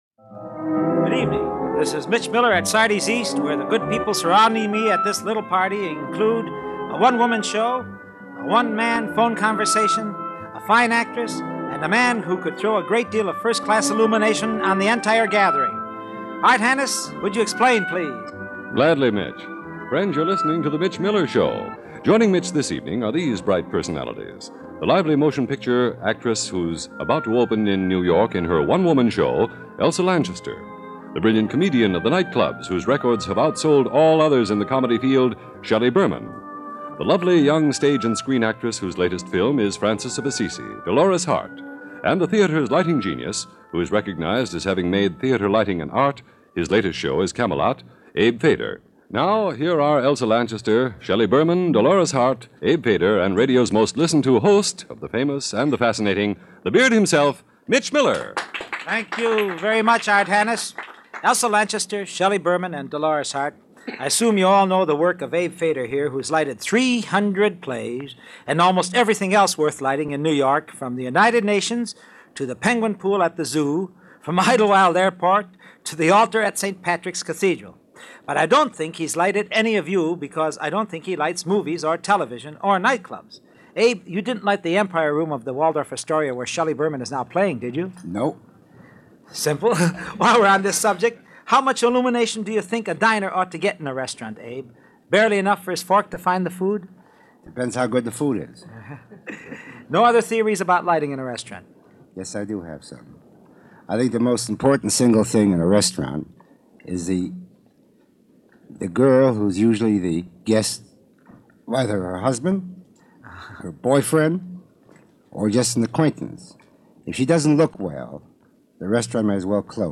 A few legendary names this week, interviewed by the inimitable Mitch Miller on his weekly radio program Sunday Evening With Mitch, which ran from the mid-1950s to the early 1960s.